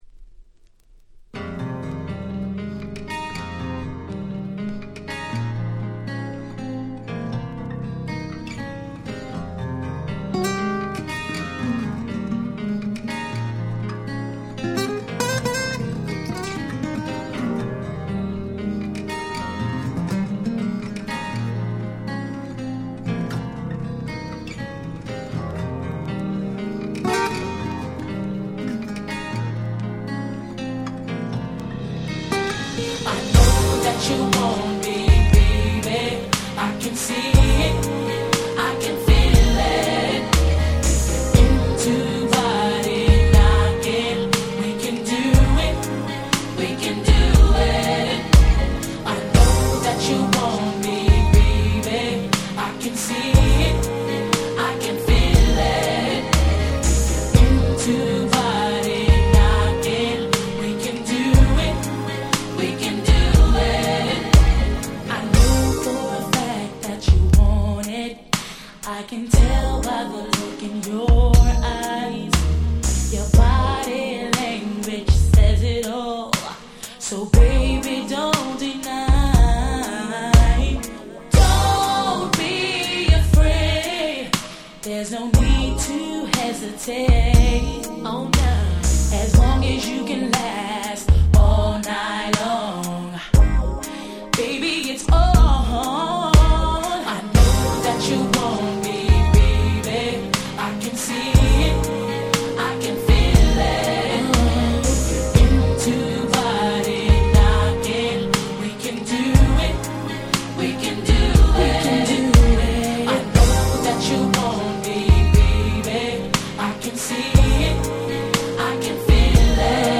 96' Very Nice Slow Jam / R&B / Hip Hop Soul !!